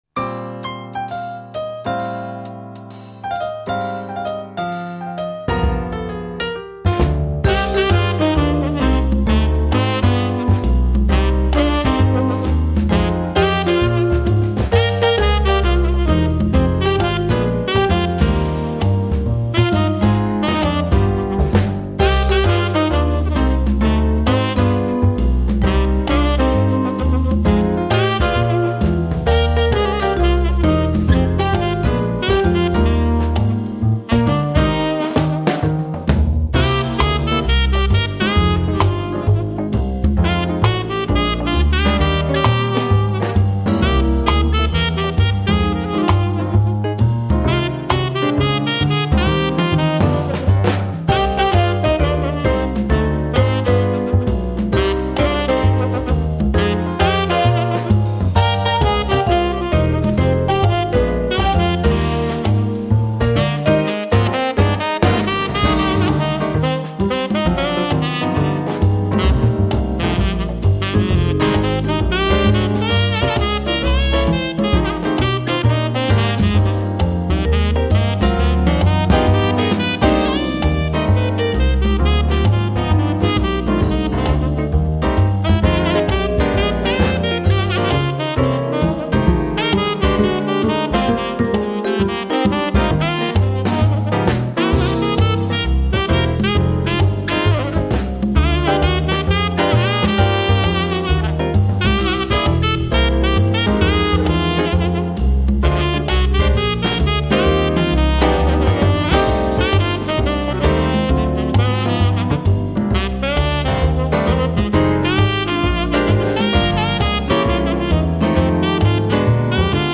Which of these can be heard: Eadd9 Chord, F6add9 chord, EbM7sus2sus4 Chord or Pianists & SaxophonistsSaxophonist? Pianists & SaxophonistsSaxophonist